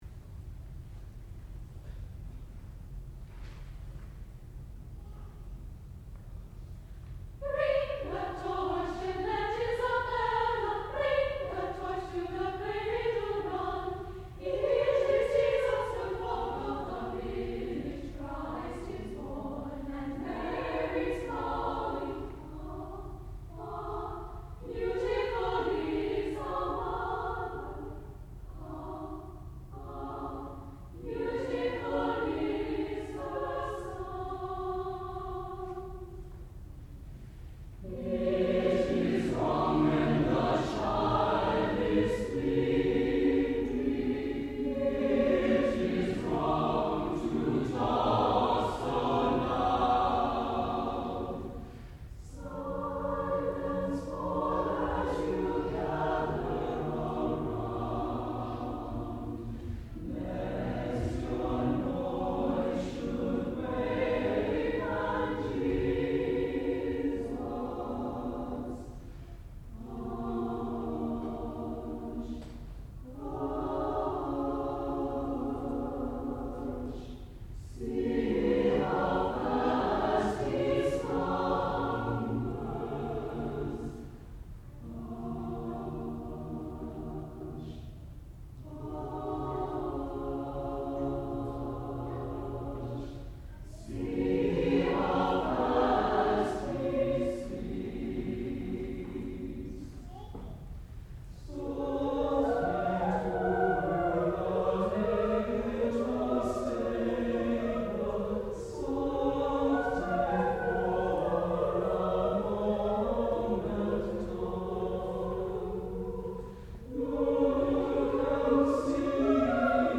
sound recording-musical
classical music
The Rice Chorale (performer).